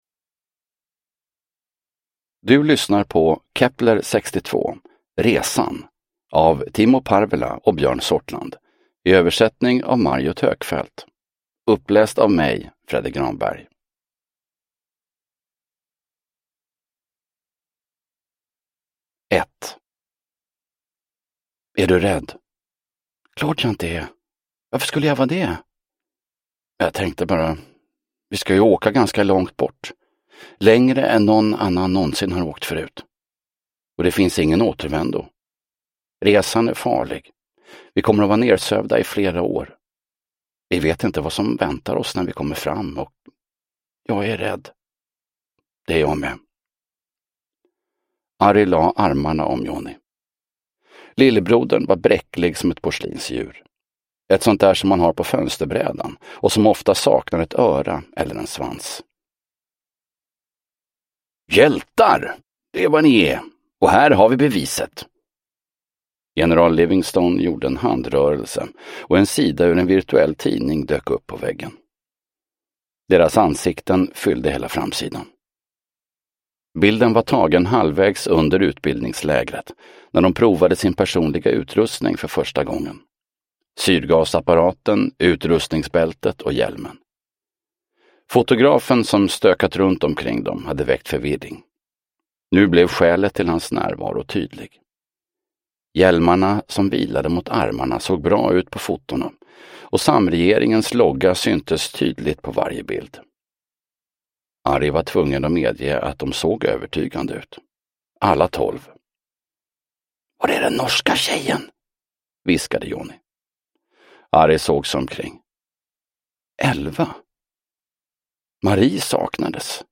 Resan – Ljudbok – Laddas ner